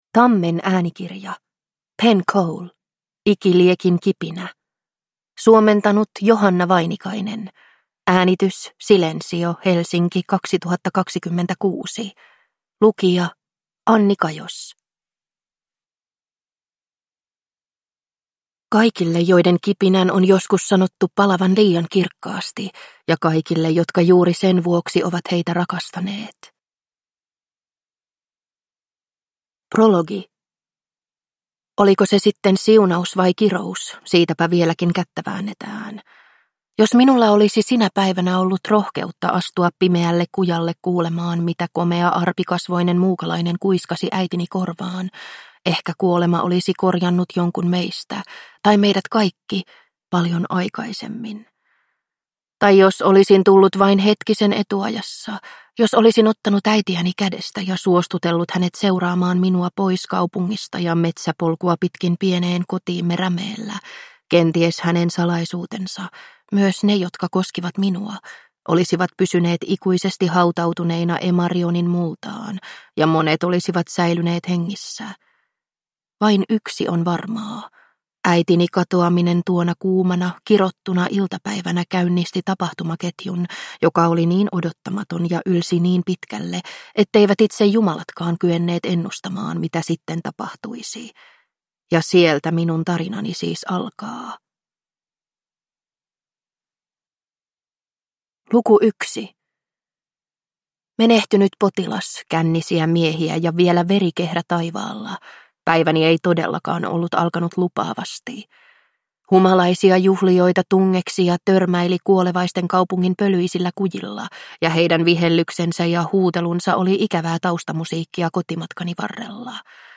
Ikiliekin kipinä – Ljudbok